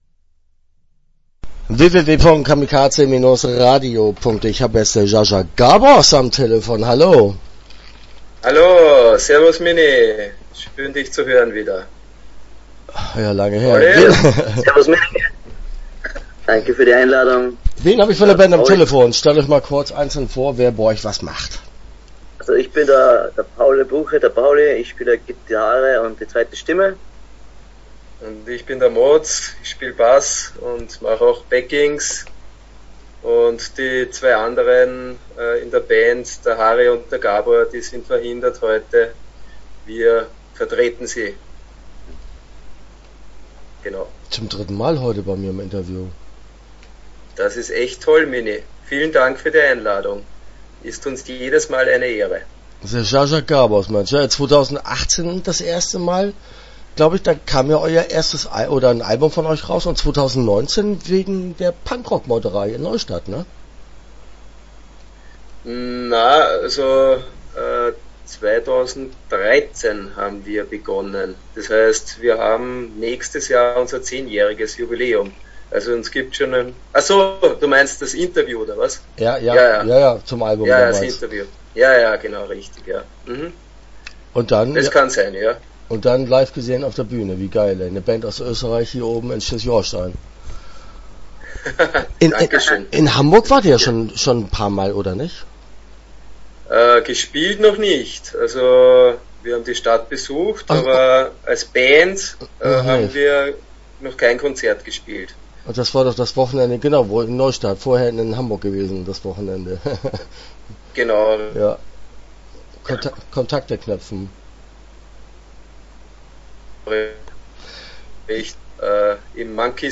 The Zsa Zsa Gabors - Interview Teil 1 (10:15)